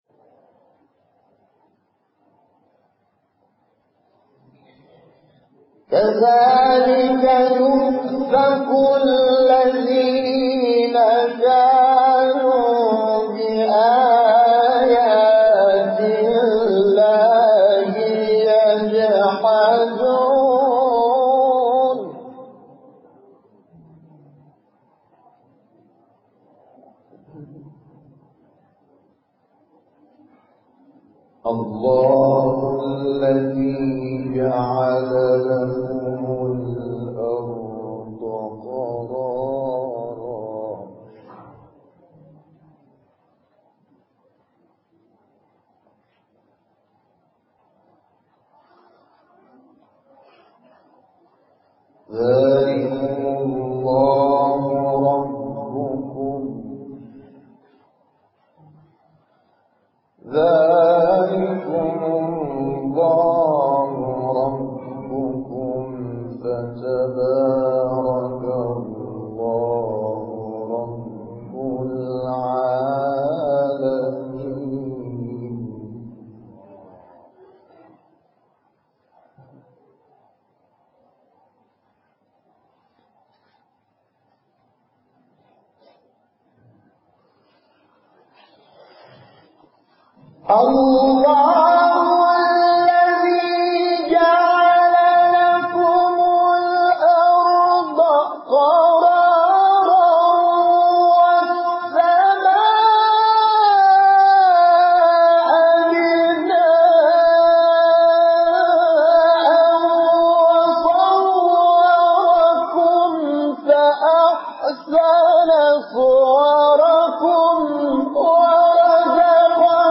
نغمات صوتی از قاریان ممتاز کشور
در مقام صبا